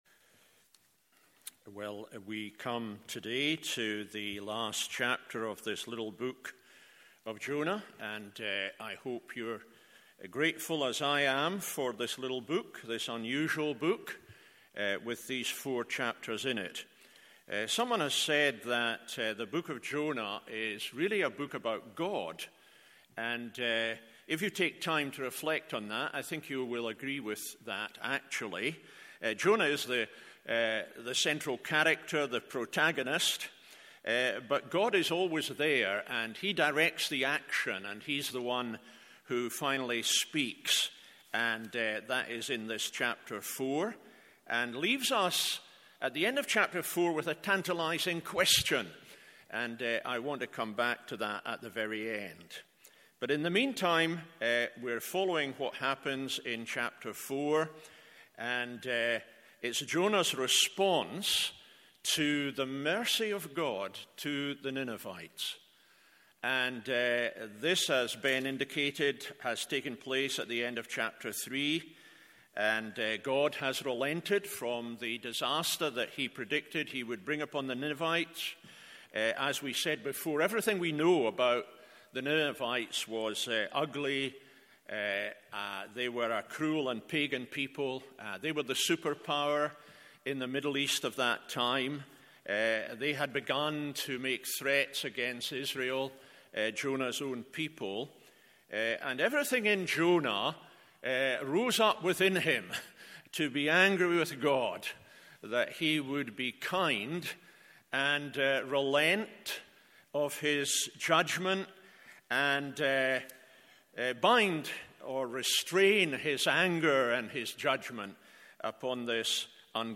MORNING SERVICE Jonah 4…